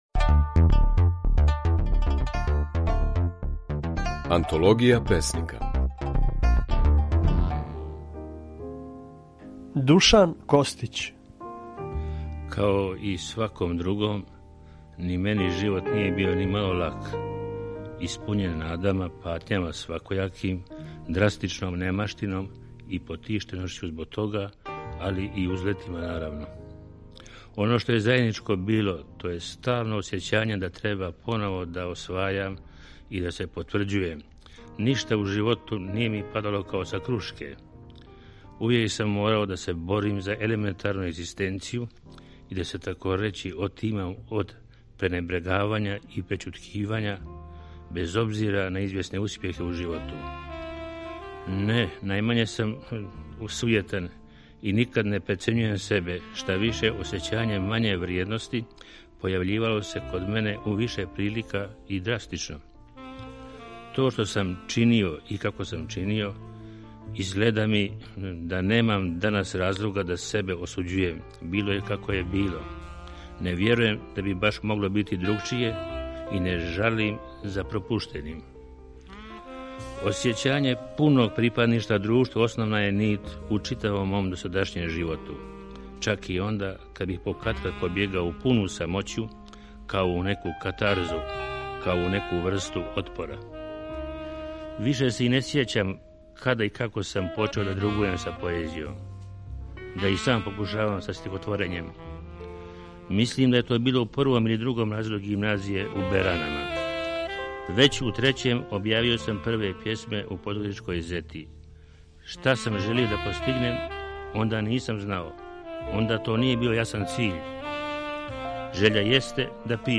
Емитујемо снимке на којима своје стихове говоре наши познати песници